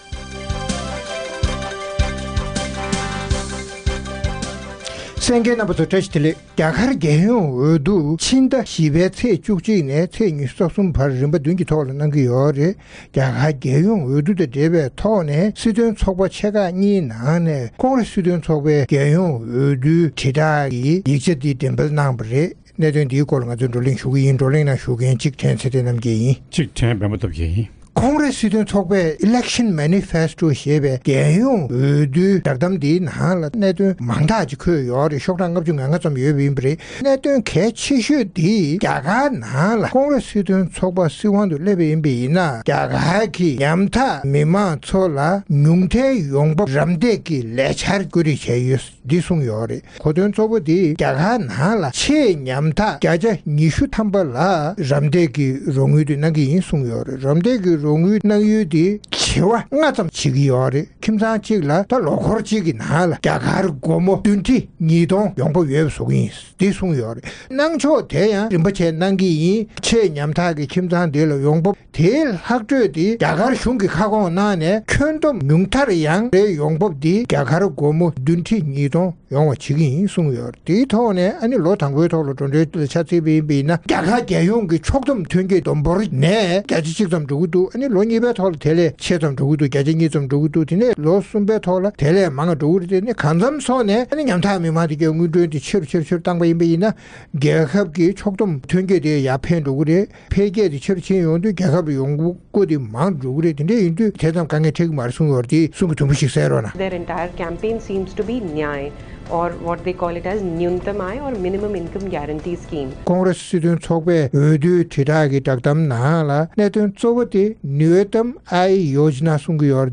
རྒྱ་གར་རྒྱལ་ཡོངས་གྲོས་ཚོགས་ཀྱི་འོས་བསྡུ་རན་པའི་སྐབས་དང་བསྟུན་Congress སྲིད་དོན་ཚོགས་པས་རྩ་ཚིག་བསྒྲགས་གཏམ་གསལ་བསྒྲགས་ཀྱི་གནད་དོན་གཙོ་གྱུར་གྱིས་སྲིད་དོན་ཚོགས་པ་ཁག་གིས་འོས་བསྡུའི་དྲིལ་བསྒྲགས་རྒྱ་ཆེ་གནང་མུས་ཀྱི་སྐོར་རྩོམ་སྒྲིག་འགན་འཛིན་རྣམ་པས་བགྲོ་གླེང་གནང་བ་གསན་རོགས་གནང་།